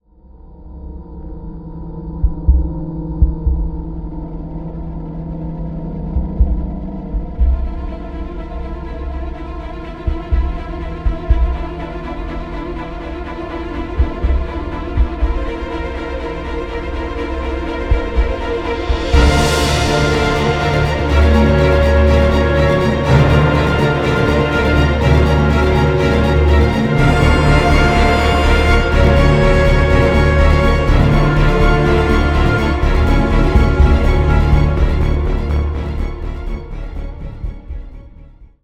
blending electronic sounds and acoustic strings